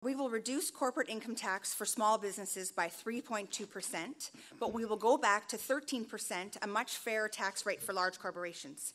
The Haldimand-Norfolk candidates spoke on the topic at the Royal Canadian Legion in Simcoe on Thursday night.